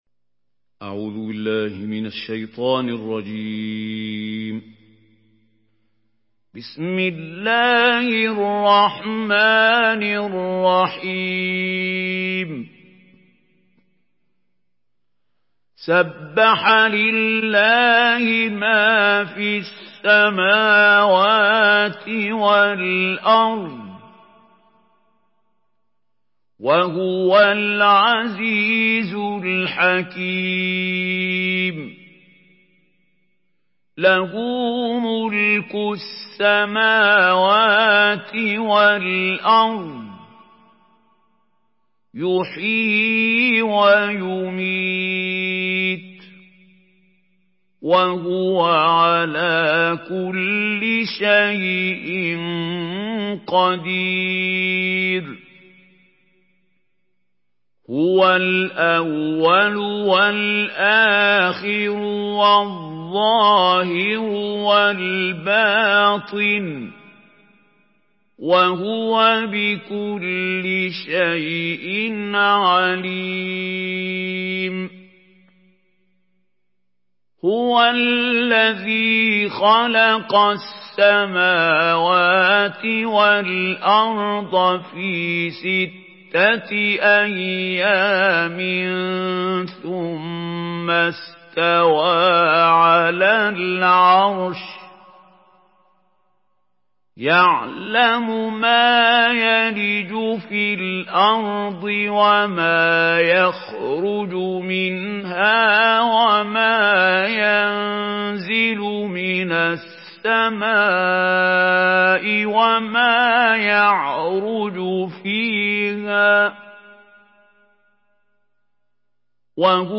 Surah Al-Hadid MP3 in the Voice of Mahmoud Khalil Al-Hussary in Hafs Narration
Surah Al-Hadid MP3 by Mahmoud Khalil Al-Hussary in Hafs An Asim narration.
Murattal Hafs An Asim